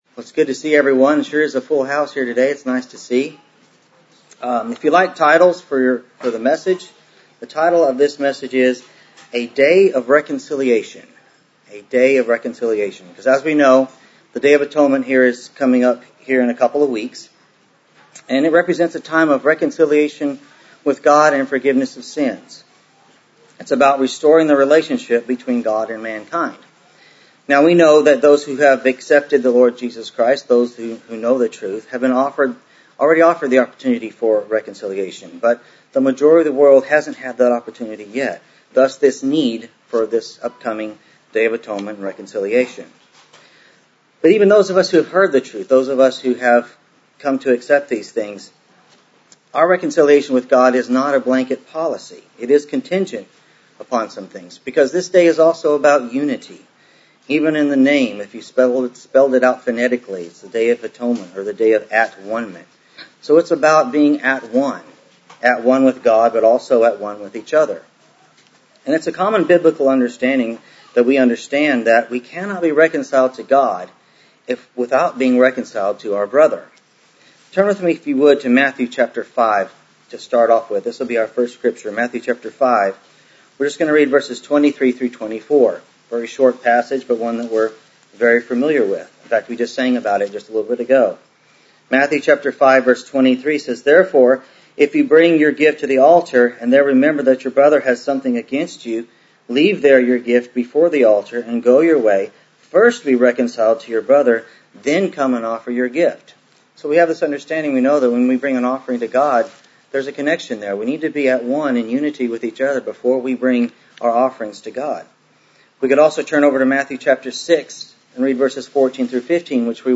Given in Dallas, TX Fort Worth, TX